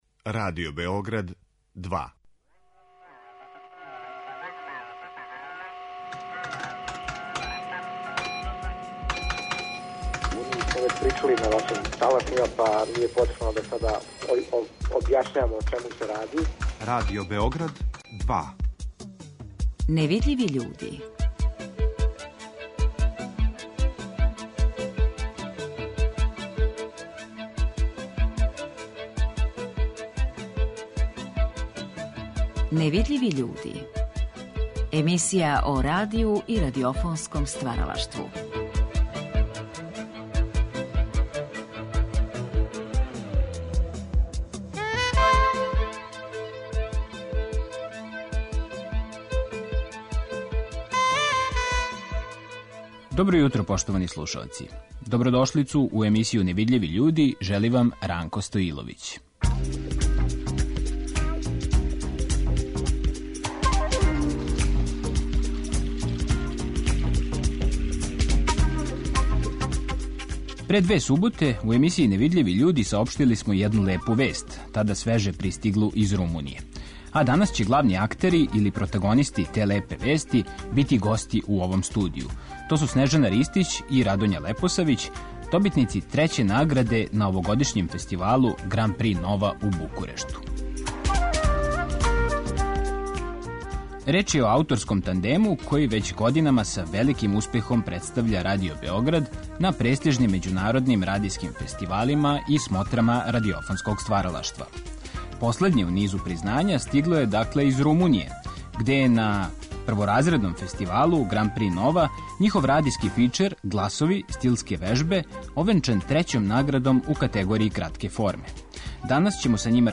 Моћи ћете да чујете и одломак награђеног фичера.